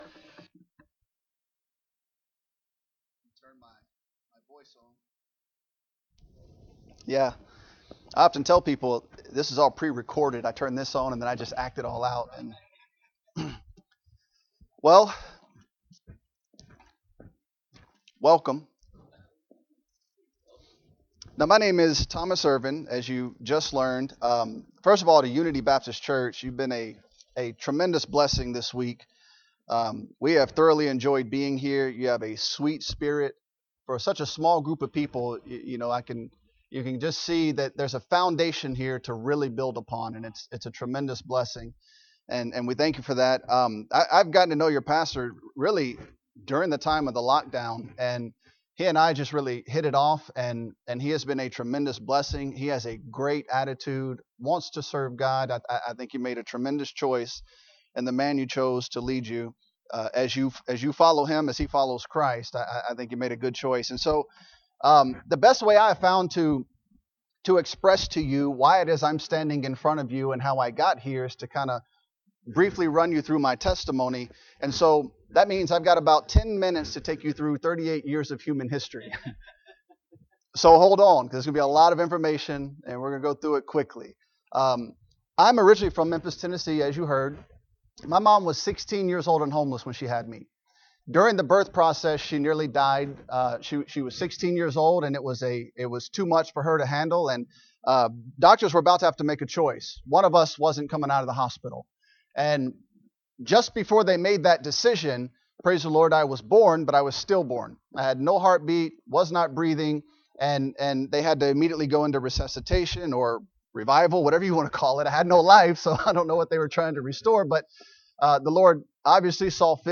John 4:27-38 Service Type: Mission Conference Bible Text